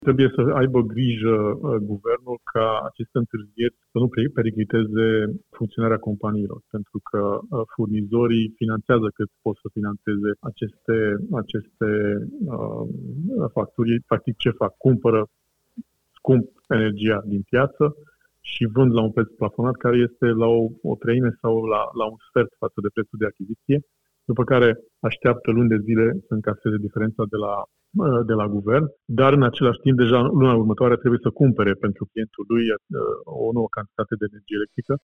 Iar în acest context, în ultima perioadă 30 de furnizori au ieșit de pe piață din cauza costurilor mari, spune la Europa FM vicepreședintele ANRE, Zolta Nagy: